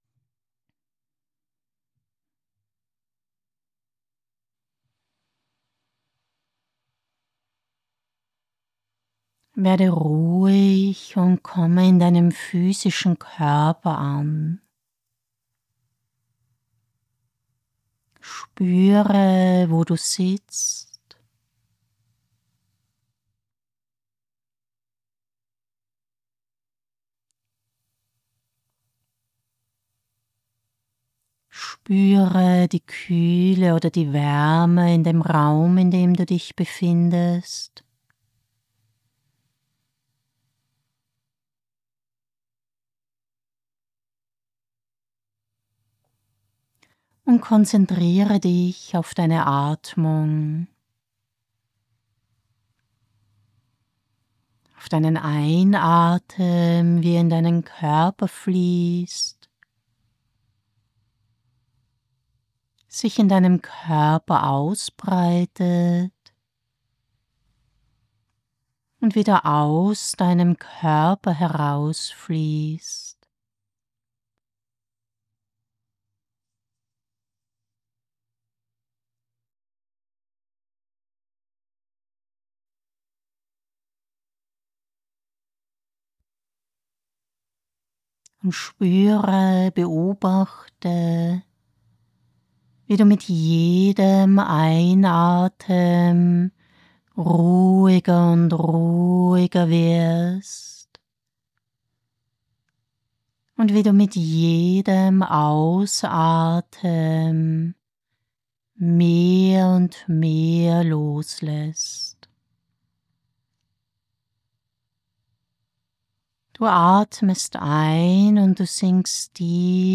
In dieser Reihe an geführten Meditationen lernst du die Anwesenheit im eigenen Leben.
Wichtig! Alle Meditationen sind ohne Hintergrundmusik.